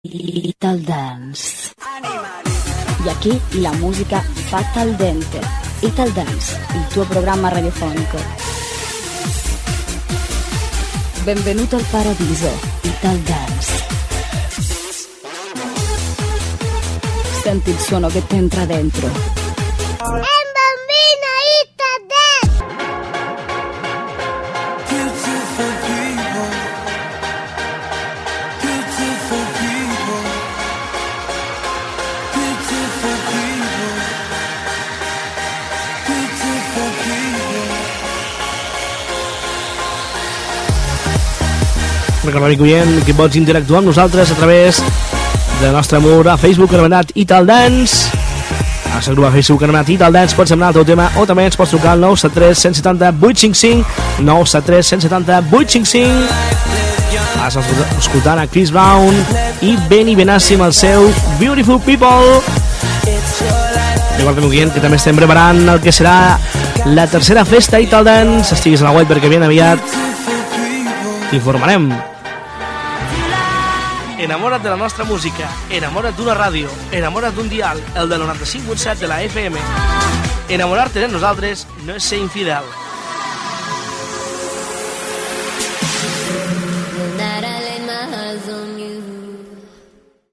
Identificació del programa, telèfon de l'emissoa, presentació d'un tema musical, anunci de la tercera festa del programa, freqüència de l'emissora, tema musical
Musical